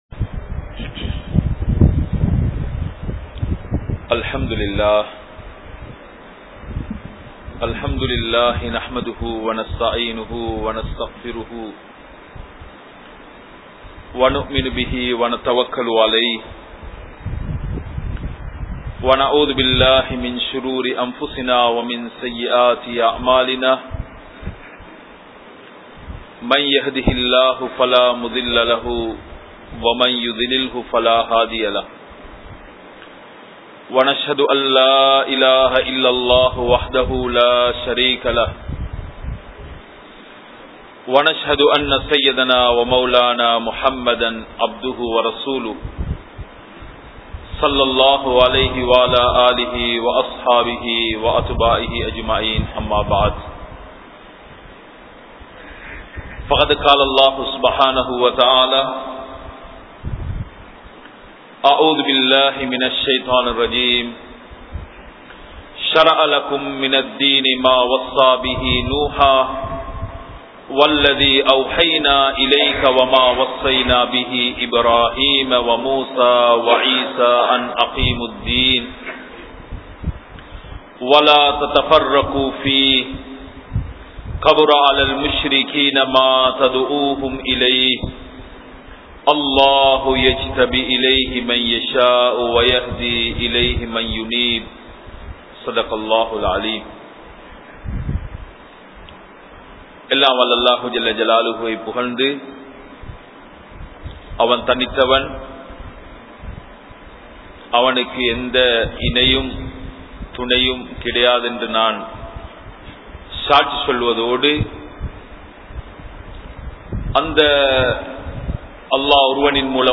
Good Habits | Audio Bayans | All Ceylon Muslim Youth Community | Addalaichenai